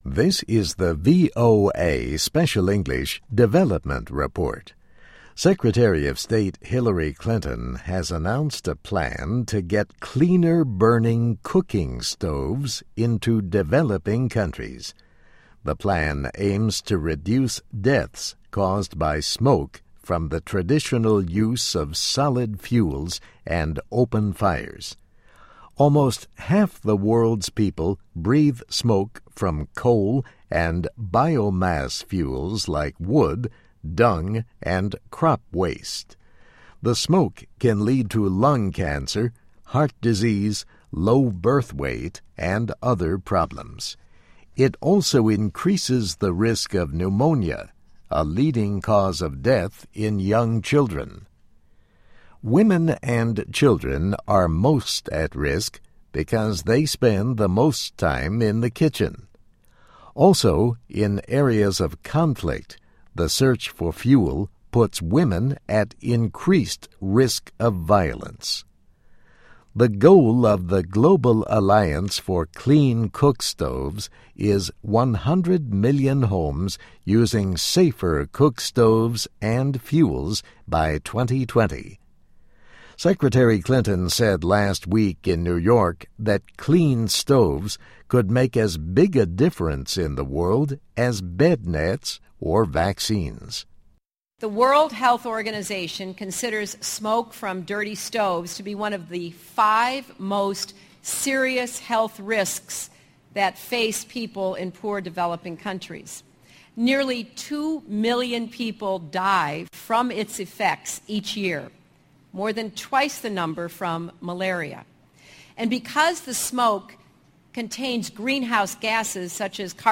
News
慢速英语:Project Seeks to Cut Deaths, Build Market for Clean Cookstoves